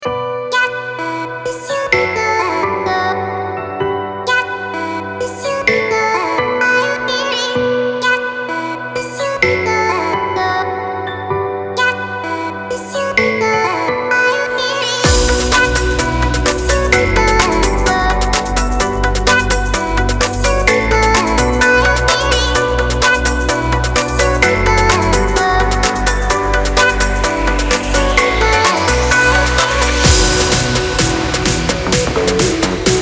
• Качество: 320, Stereo
Electronic
EDM
future house
забавный голос
Интересная электронная музыка